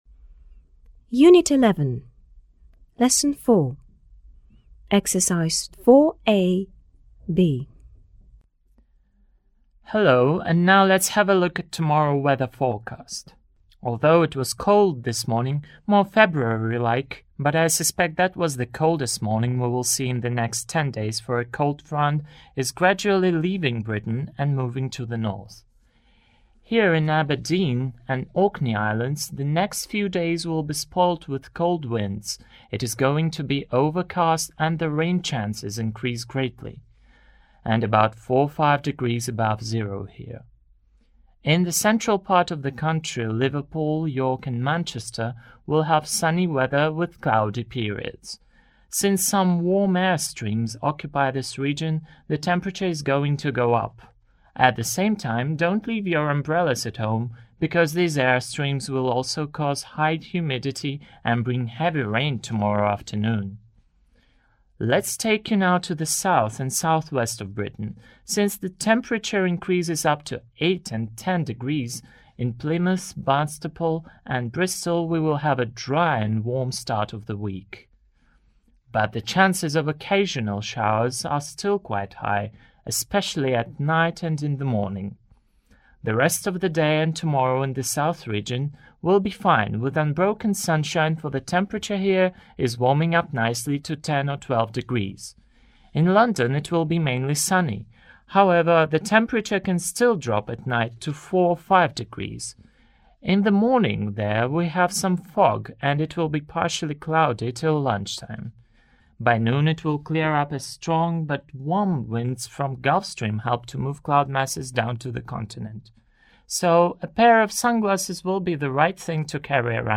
a weather forecast for Britain